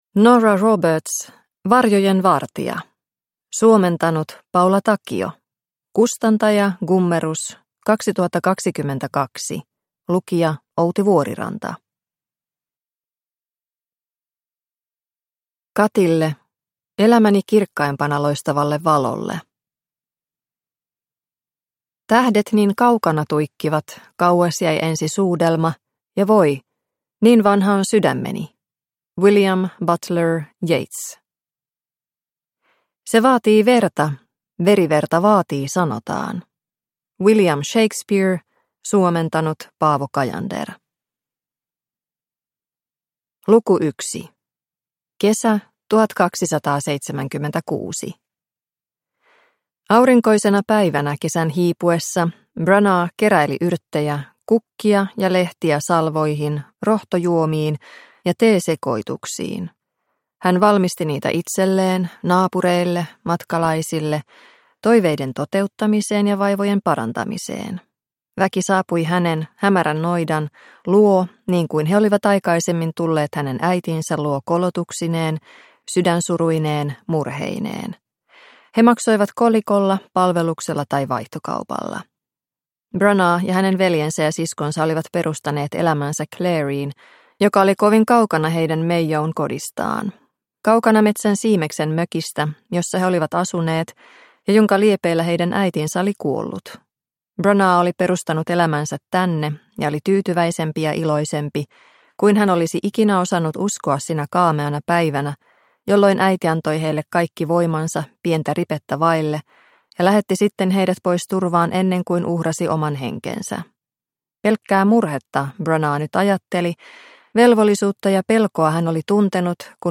Varjojen vartija – Ljudbok – Laddas ner